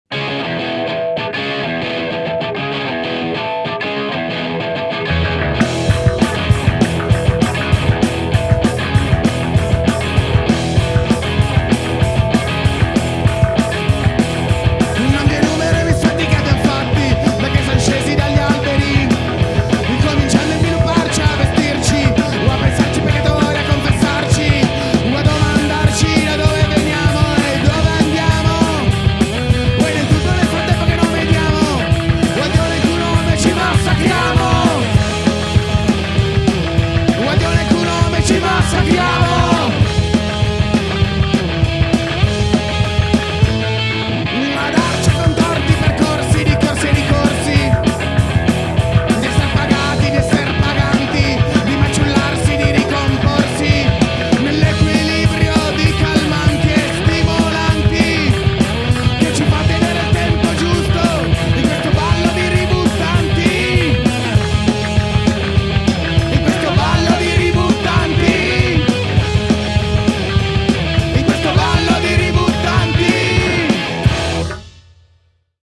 Ruvidi e grezzi, ma sempre con tante cose da dire.